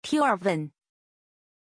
Aussprache von Tjorven
pronunciation-tjorven-zh.mp3